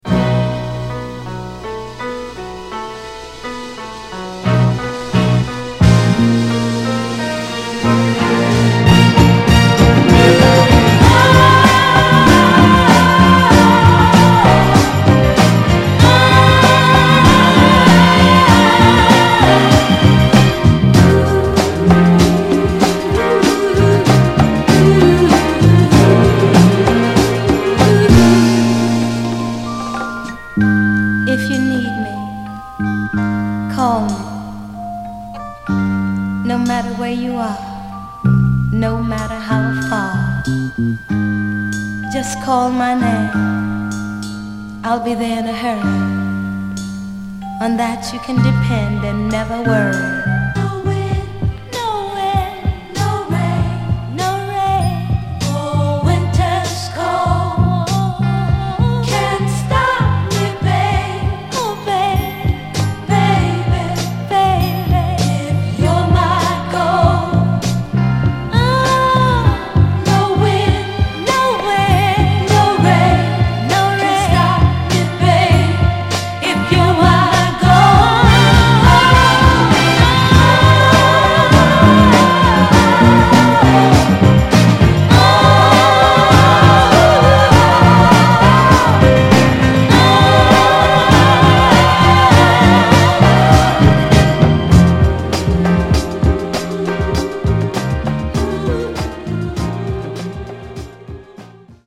盤はいくつか細かいスレ、ヘアーラインキズ箇所ありますが、グロスがありプレイ良好です。
※試聴音源は実際にお送りする商品から録音したものです※